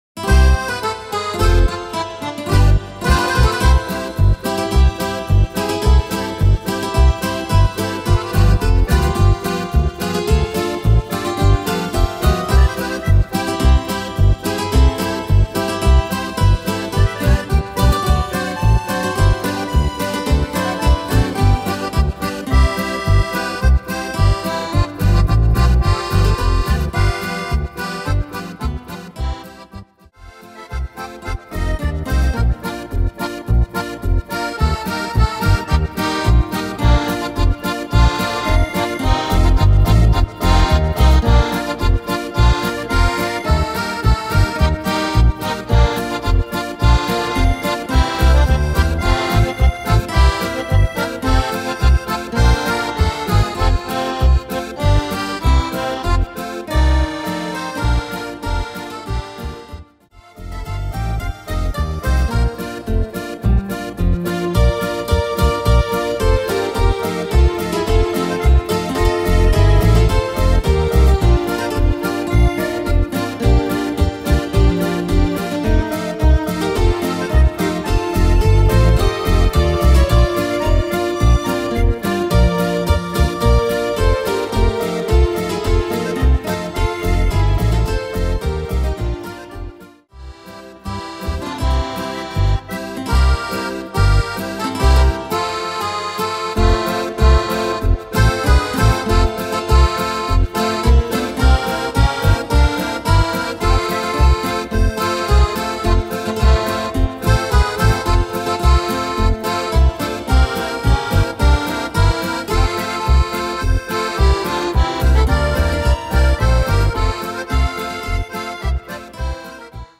Tempo: 108 / Tonart: D-Dur